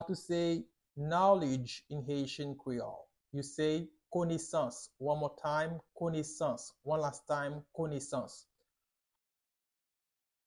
Pronunciation:
18.How-to-say-Knowledge-in-Haitian-Creole-–-Konesans-with-pronunciation.mp3